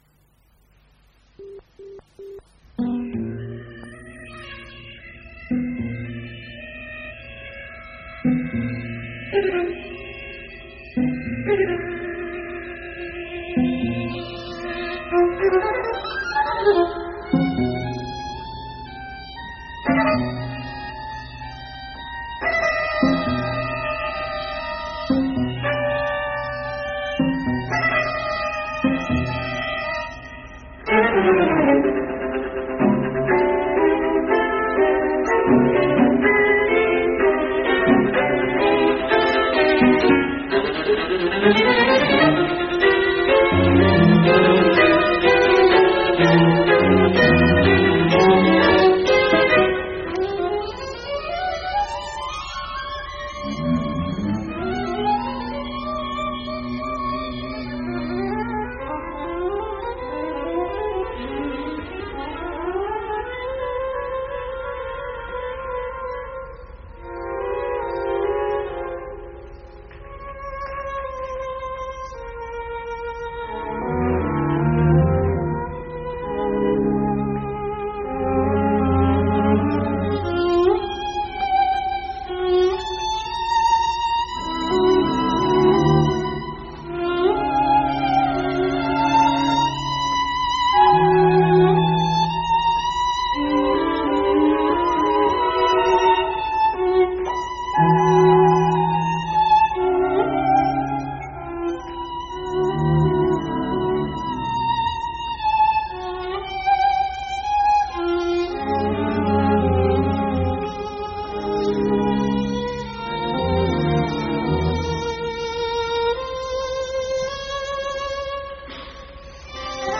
privater Mittschnitt